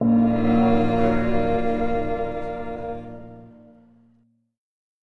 Play, download and share holy chant original sound button!!!!
holy-chant.mp3